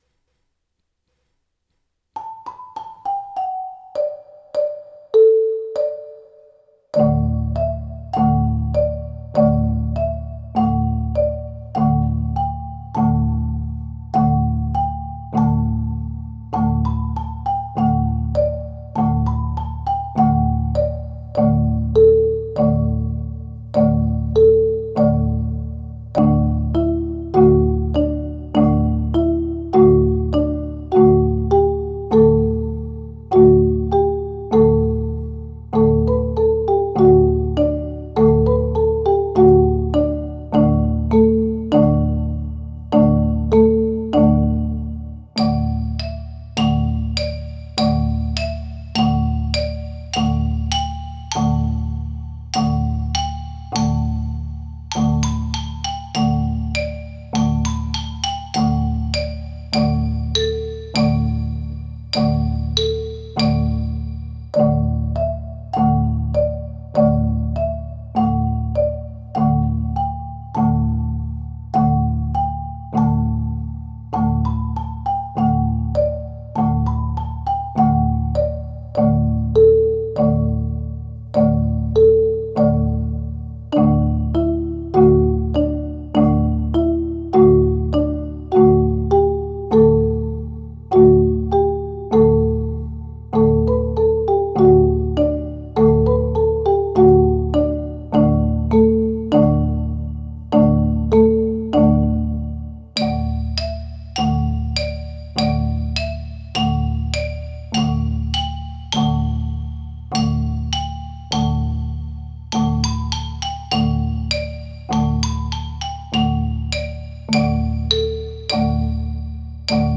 Bruder-Jakob-Kanontanz.mp3